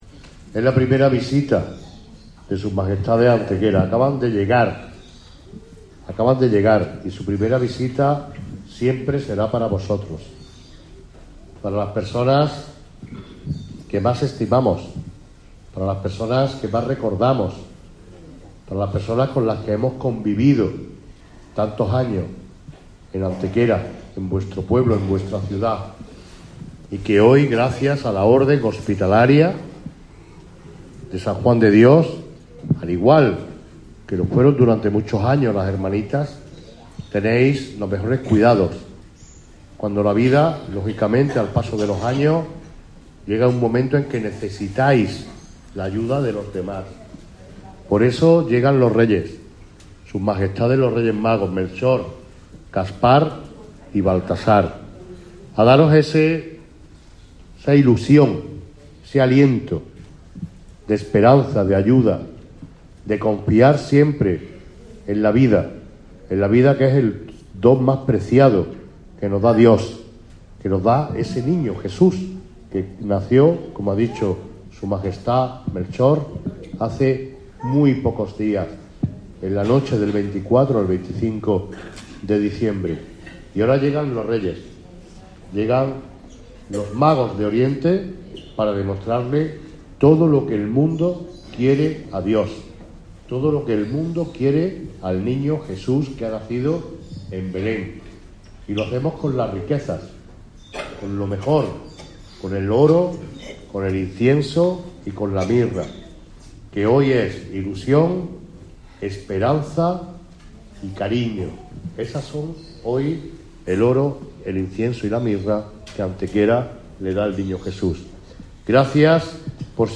El Alcalde ofreció, tanto a Sus Majestades como al resto del séquito real, unas palabras de bienvenida en la residencia de ancianos de San Juan de Dios que estuvieron dedicadas a los internos del centro: "Los Reyes Magos llegan a daros esa ilusión, ese aliento de esperanza y ayuda, de confiar siempre en la vida que es el don más preciado que nos da Dios.
Cortes de voz